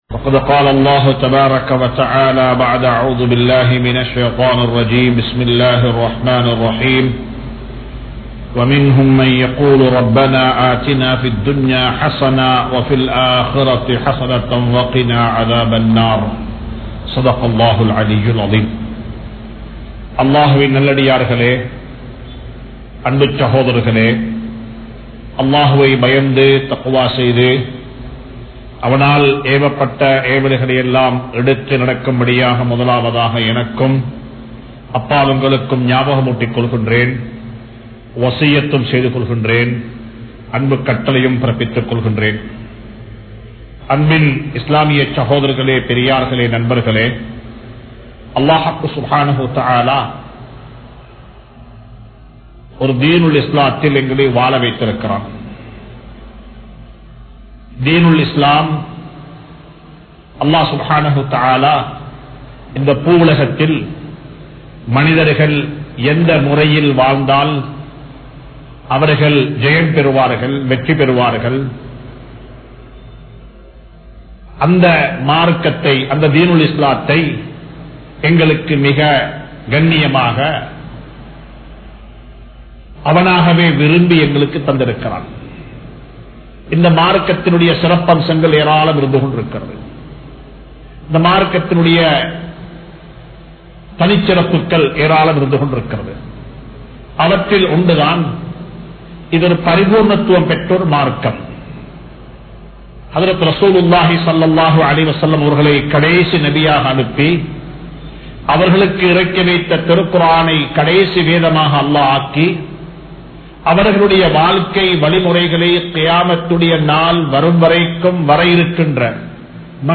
Seyalhalai Nanmaiyaakkungal (செயல்களை நன்மையாக்குங்கள்) | Audio Bayans | All Ceylon Muslim Youth Community | Addalaichenai
Kollupitty Jumua Masjith